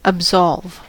absolve: Wikimedia Commons US English Pronunciations
En-us-absolve.WAV